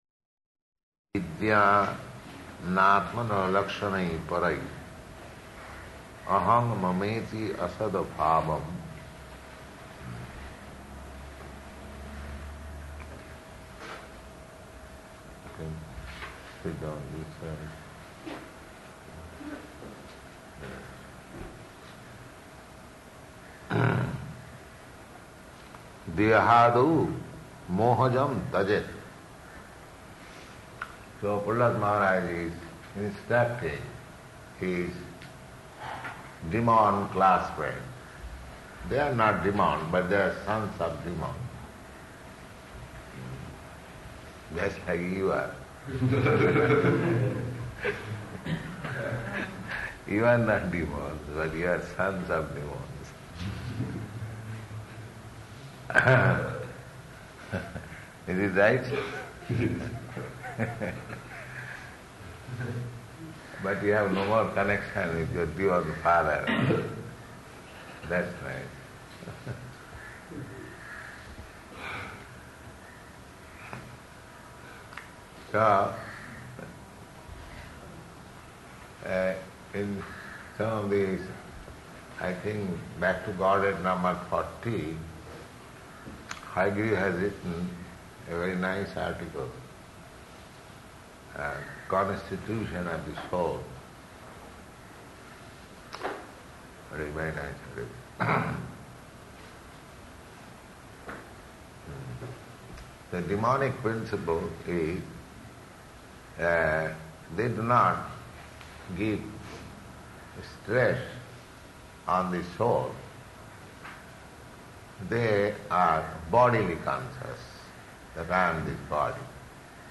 Type: Srimad-Bhagavatam
Location: Bombay